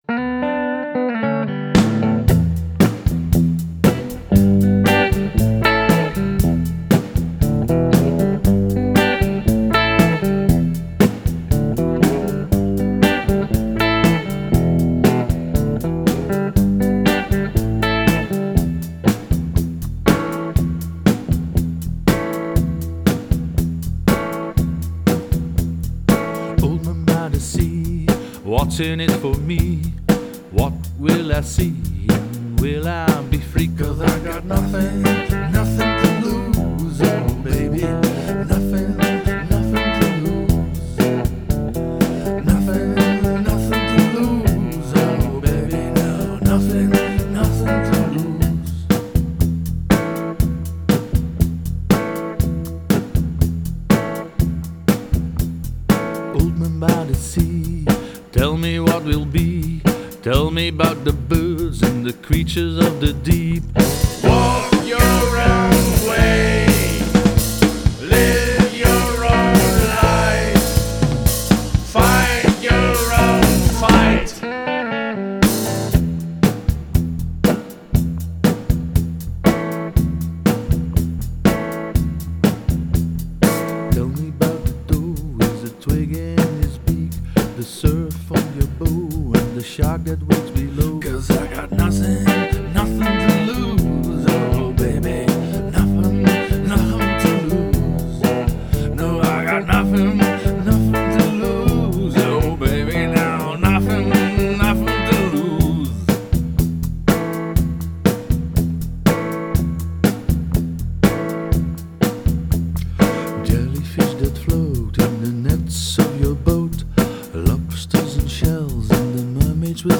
vocals
guitar
drums except on Scillies
Recorded in Studio PH14 ASE on Texel.